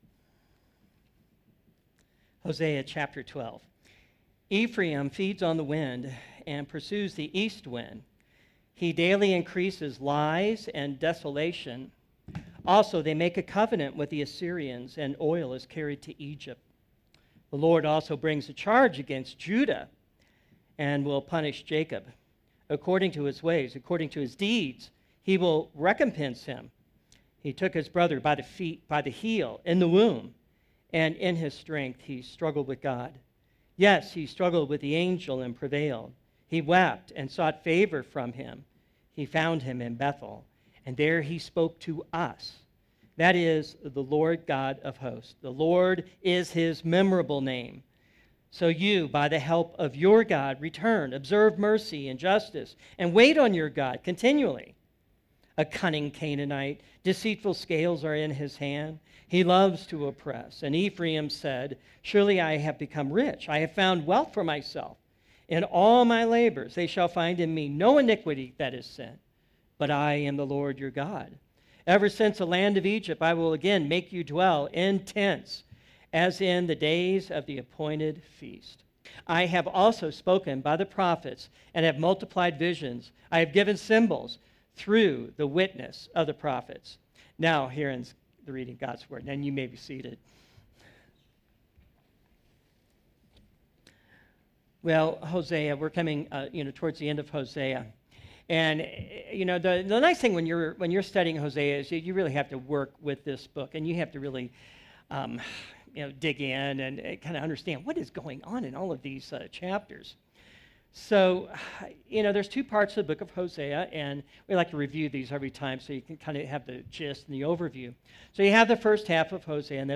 Sermons - Redeemer Presbyterian Church